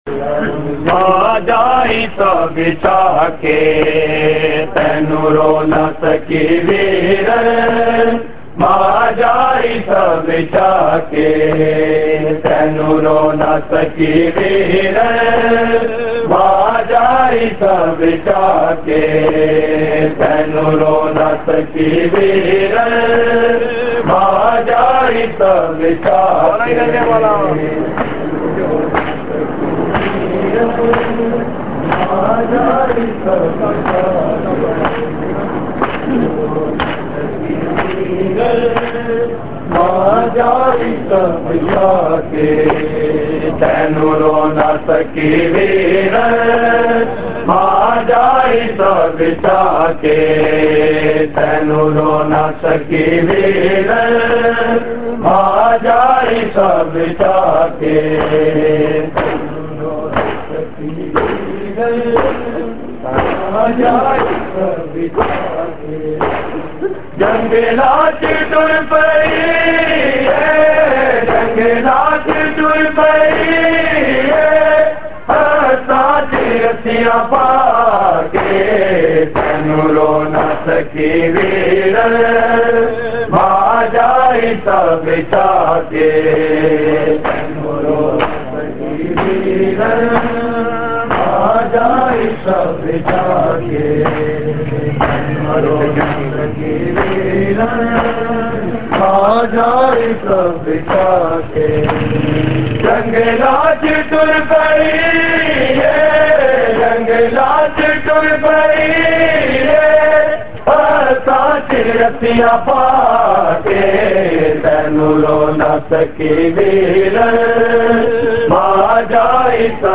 Markazi Matmi Dasta, Rawalpindi
Recording Type: Live
Location: Rawalpindi